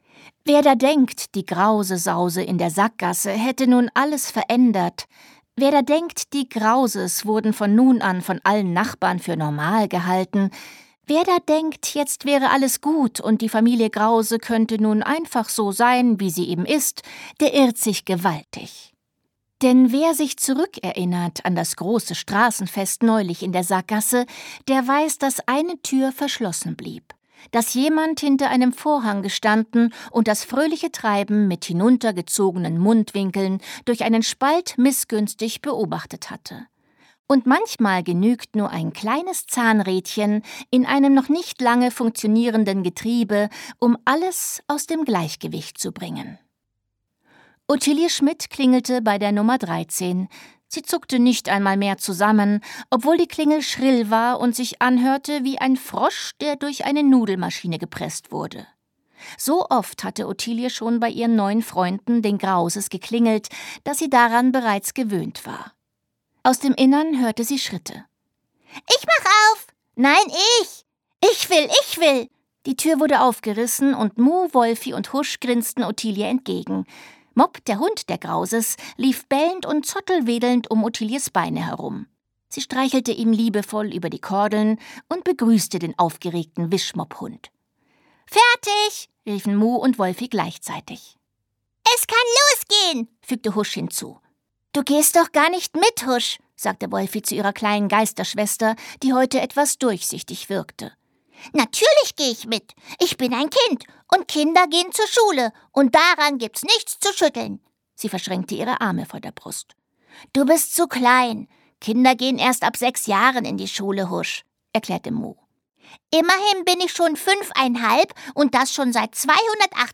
Details zum Hörbuch
Sprecher Sabine Bohlmann